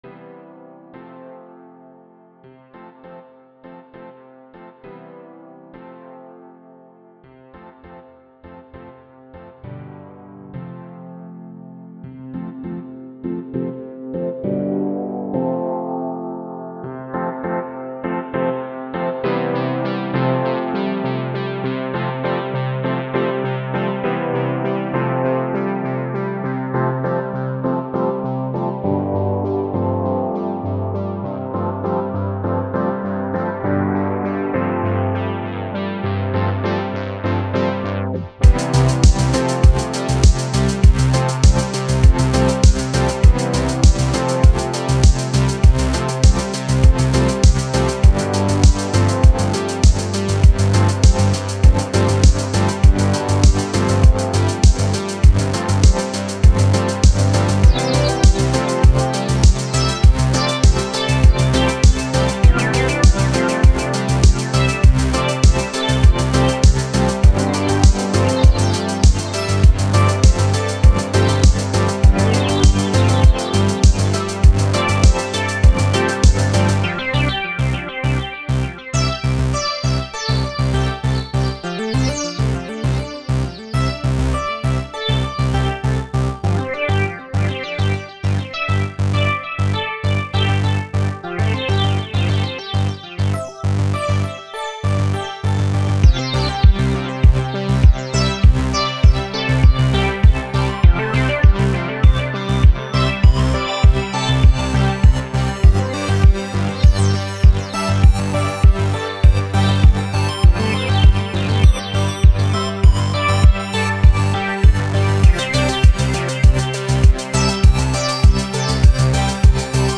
Spent all night trying to mix this but idk. i think it went out fine (im not convinced on that clap tho)
Music / Techno
techno
futuristic